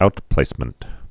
(outplāsmənt)